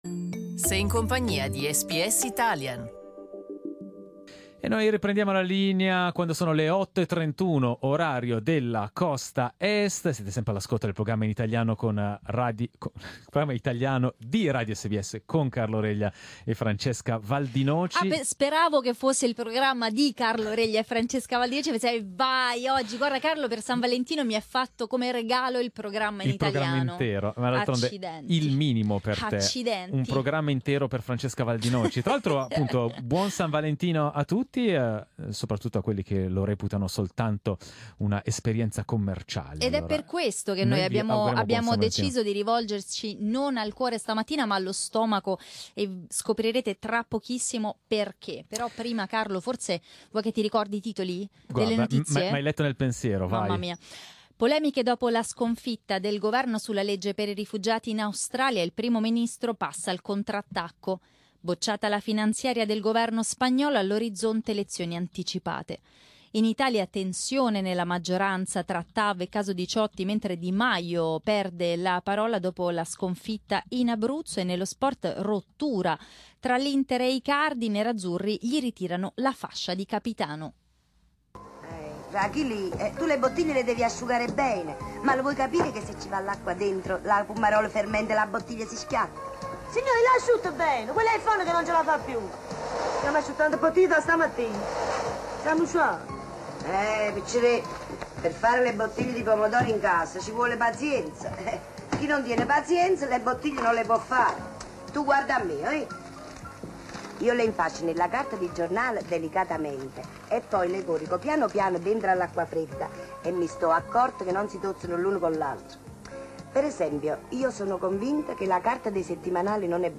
We asked our audience and found the passata tradition is alive and well! Click on the audio player above to listen to the talkback.